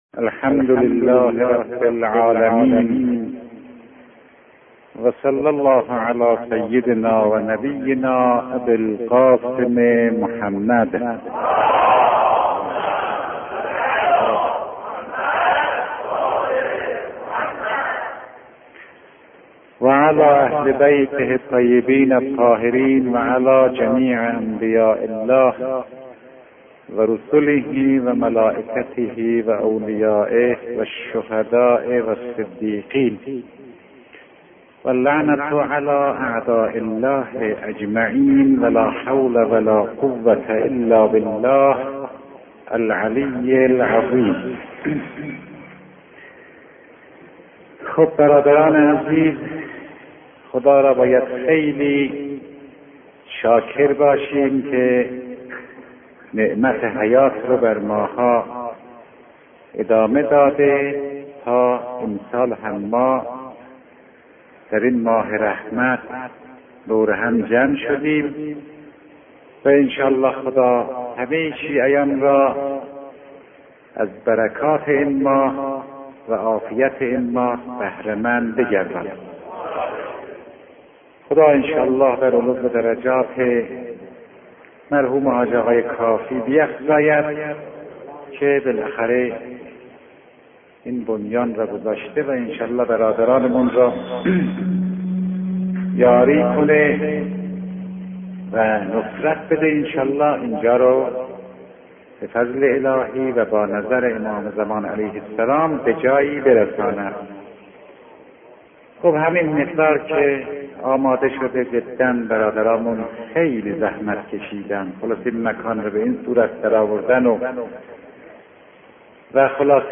این فایل صوتی، گزیده‌ای از سخنرانی مرحوم آیت‌الله فاطمی‌نیا است که در ماه رمضان به موضوع اخلاق در قرآن می‌پردازد.